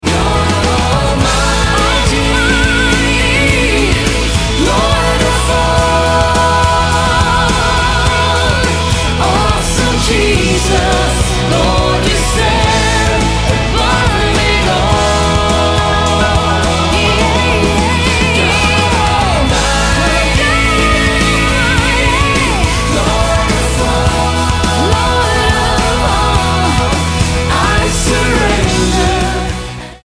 Worship Album